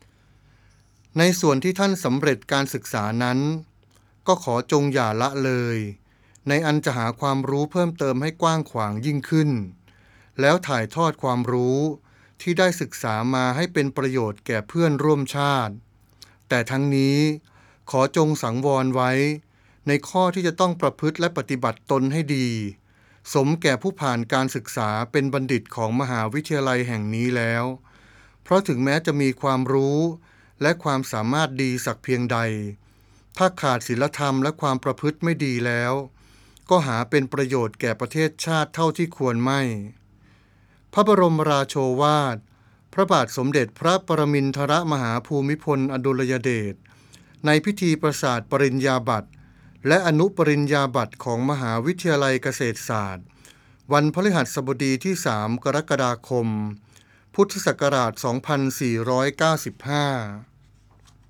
พระบรมราโชวาท
ในพิธีประสาทปริญญาบัตรและอนุปริญญาบัตร ของมหาวิทยาลัยเกษตรศาสตร์